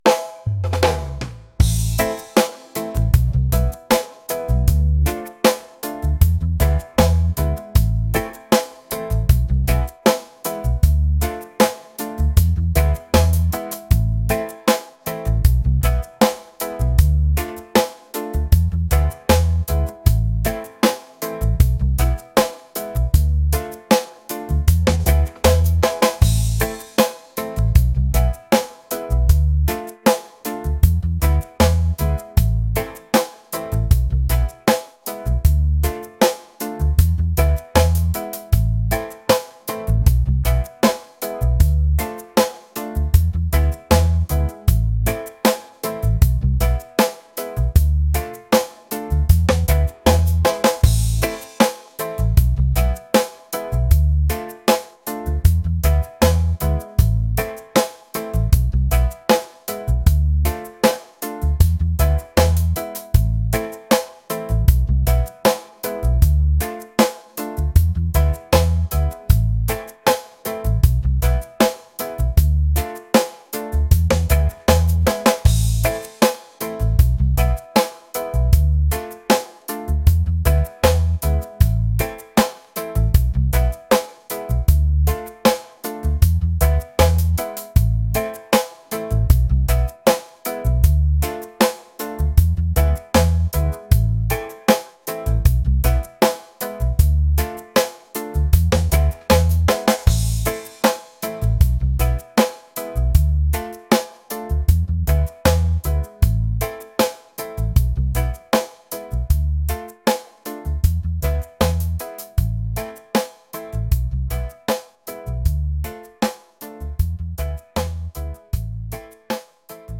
reggae | smooth | laid-back